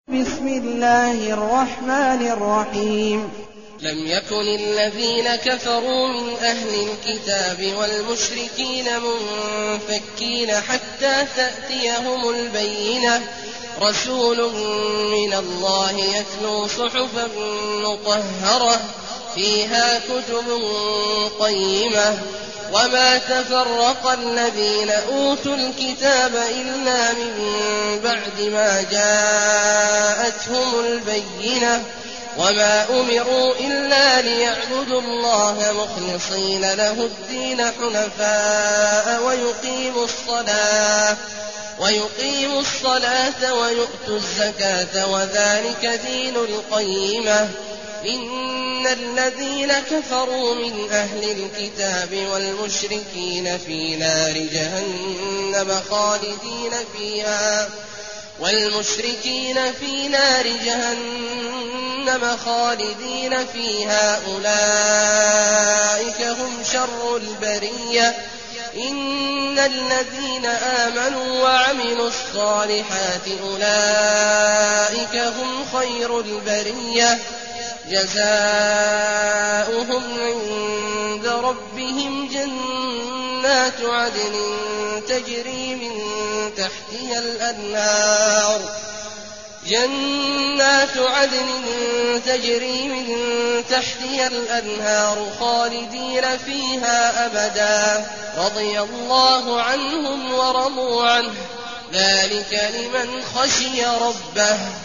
المكان: المسجد النبوي الشيخ: فضيلة الشيخ عبدالله الجهني فضيلة الشيخ عبدالله الجهني البينة